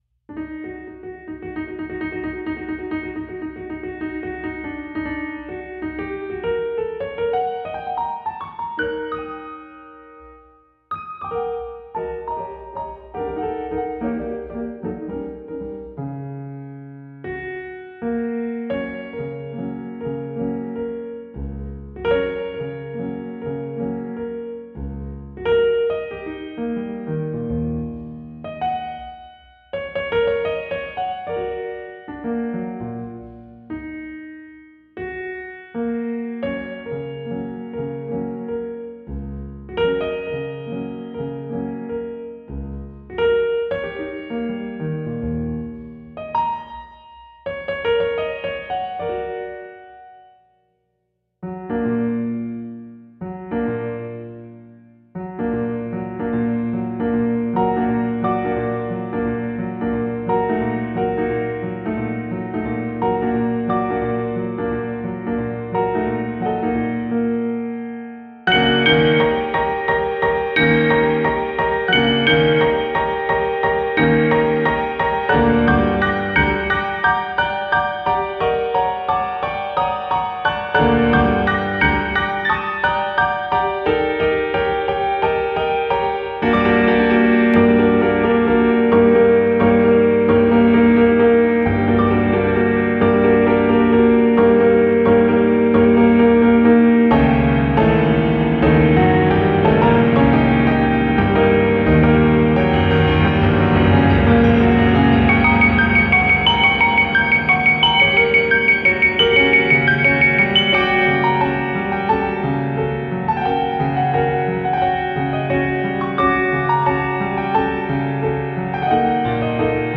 neo-classical solo piano